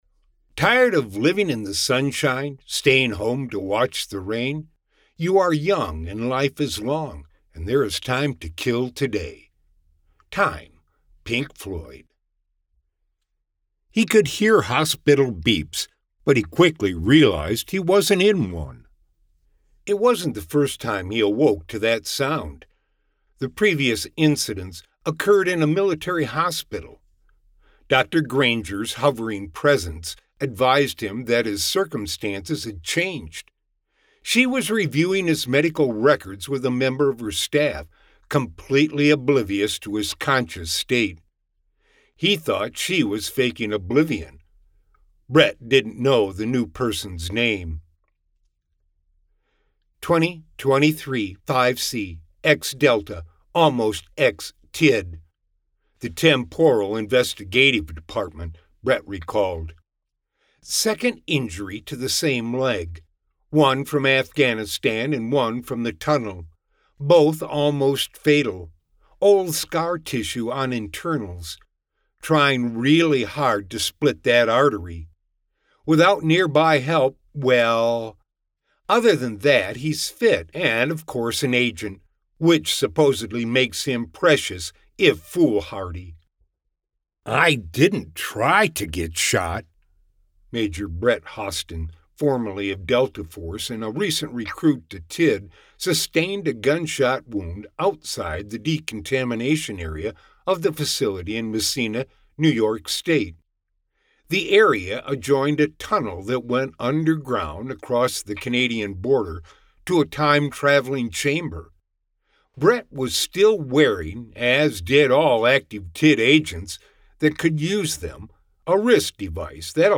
Zodiac is available on Amazon and as an audio book on Apple and other stores.